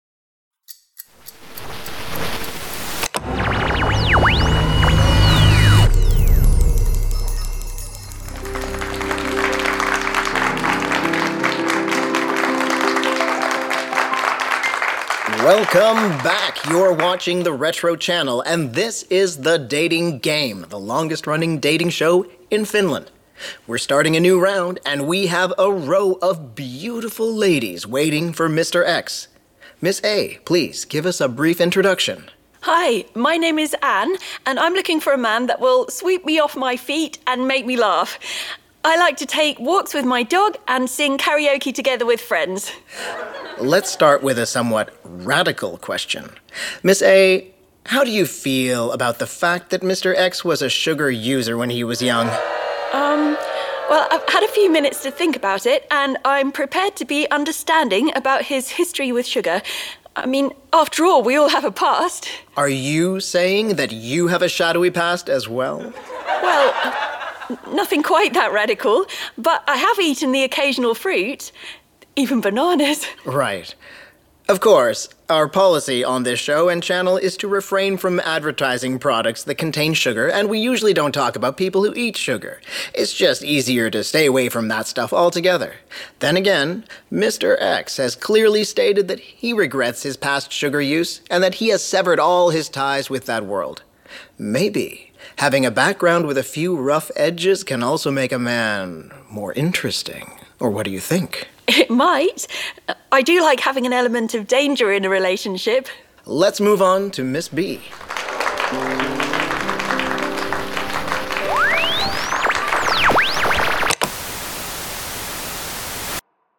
These audio drama pieces represent exaggerated versions of prevailing views of the future.